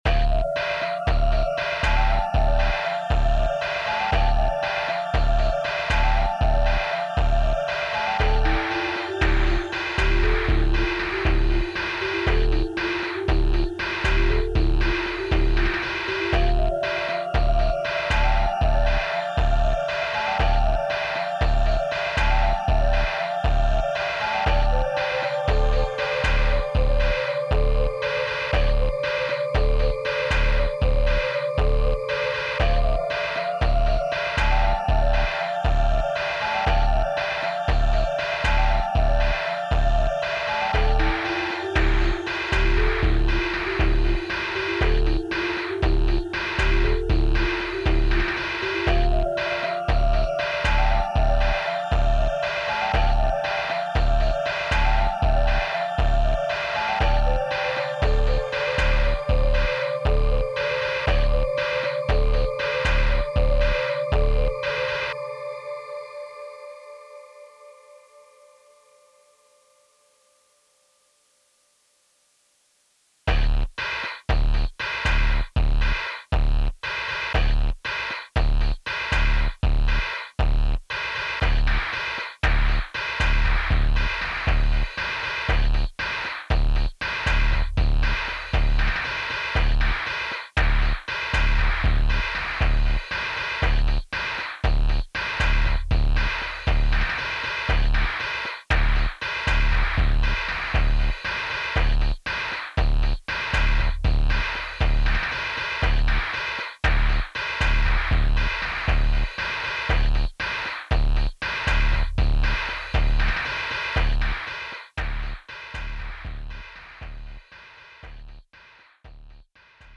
apps: Octachron, Posion-202, Tails - Dual reverb, DrumLab, Koala, Mixbox, TB Barricade, Wavebox -The Posion-202 synth, DrumLab and Koala were controlled by Octachron.
-The bass sounds were output from DrumLab.
easy jam with Octachron image: Person Q thinks Q was given less change by a cashier at a store where Q paid cash just a few minutes ago.
Receive from Bus A - 29.5 bpm - 001.mp3 4.7M